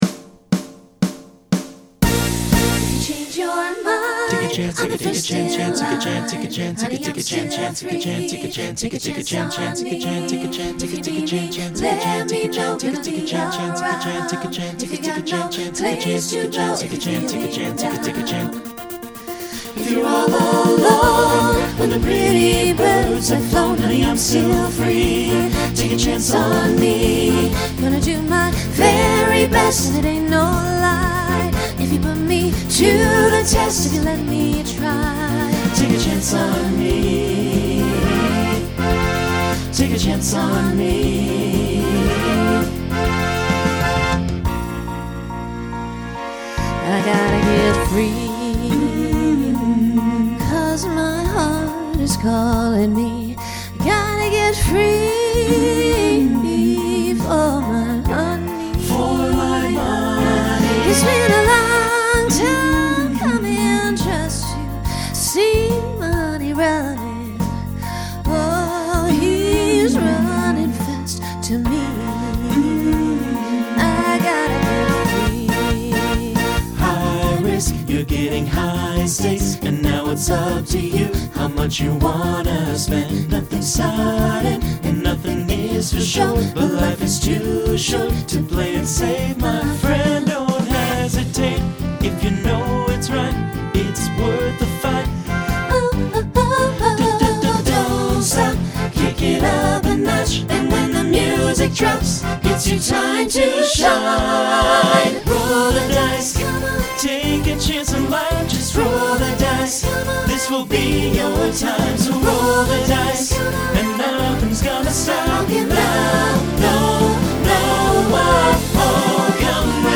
Genre Disco , Pop/Dance , Rock
Voicing SATB